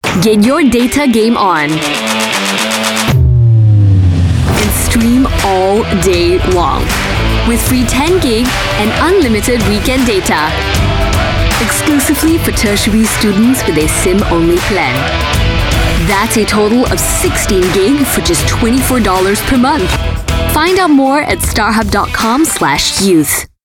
Voice Sample: Starhub Youth Perk Spotify
EN Asian EN SG
We use Neumann microphones, Apogee preamps and ProTools HD digital audio workstations for a warm, clean signal path.